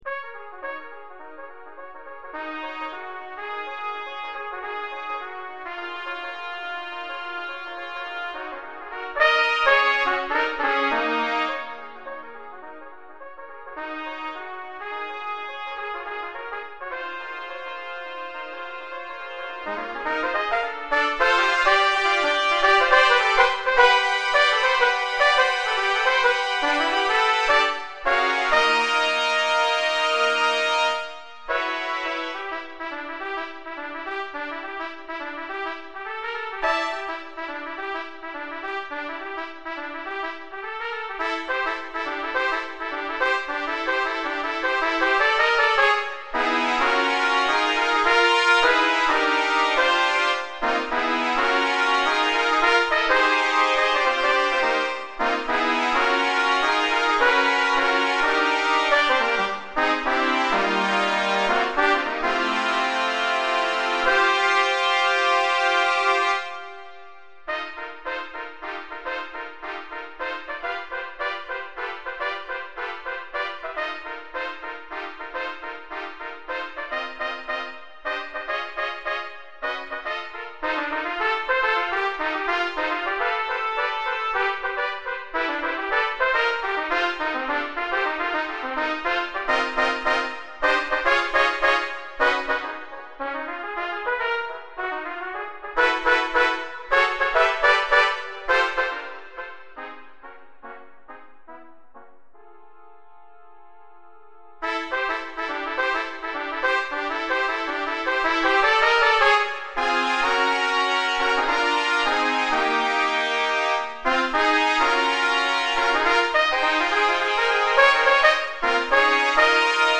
Voicing: Trumpet Choir